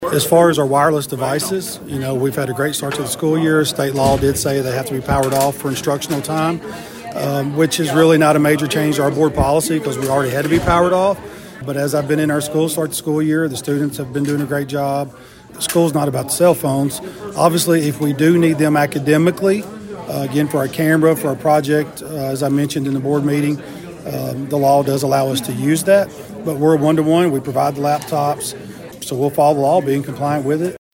Director of schools, Jeff Cupples spoke to Thunderbolt News about the policy